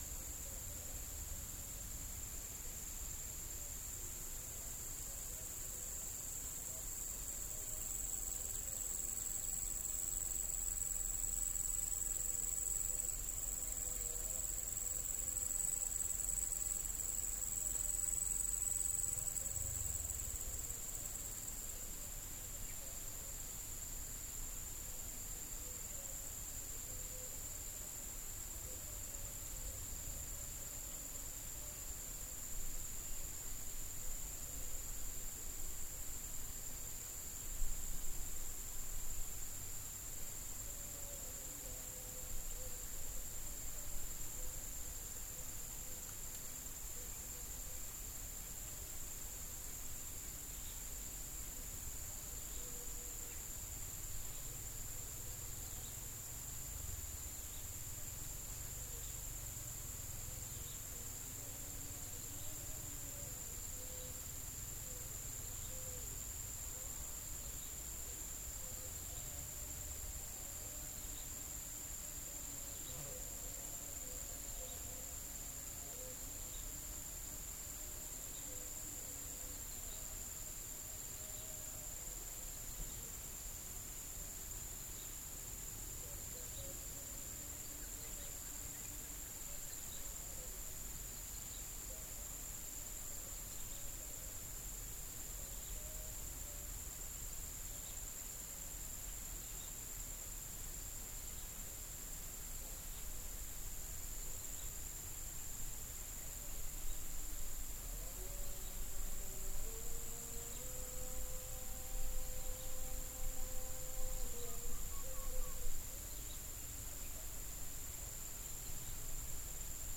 Demonstration soundscapes
anthropophony
biophony